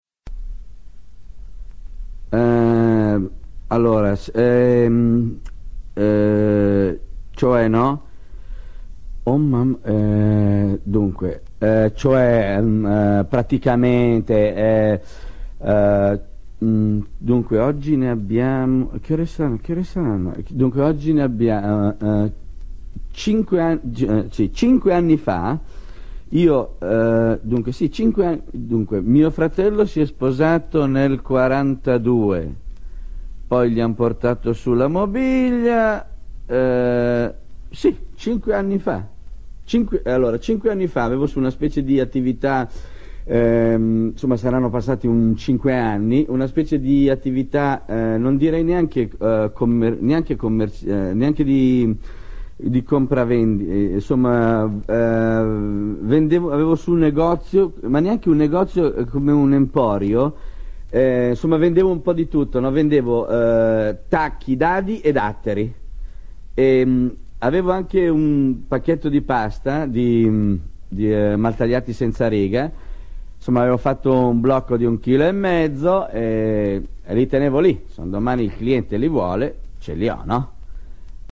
E poi questo non solo qualcosa, ci si chiede, sarà ristretto ad un ambito di genere o sarà totalmente surreale come il non-compleanno del Cappellaio Matto e della Lepre Marzolina o dadaista ed eclettico come nel famoso fondante e filosofico monologo di Renato Pozzetto?
tacchi-dadi-e-datteri-renato-pozzetto.mp3